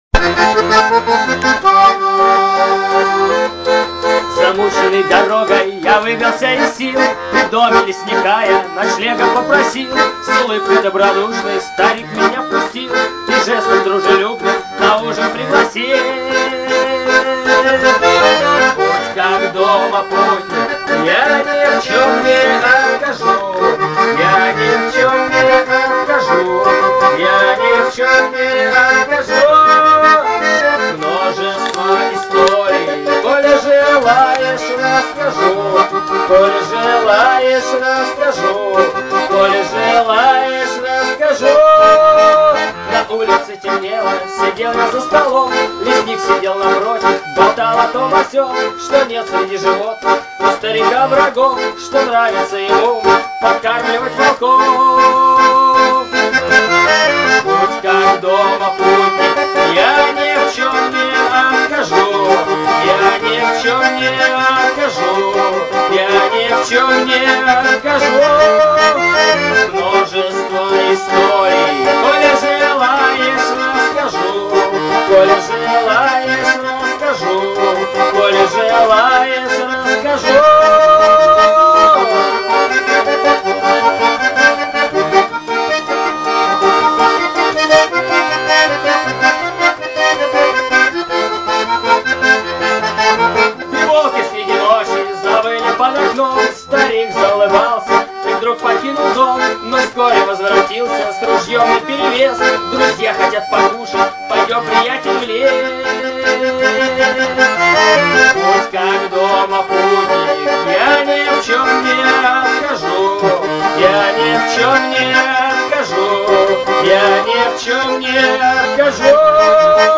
под баян